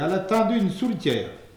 Patois - ambiance
Catégorie Locution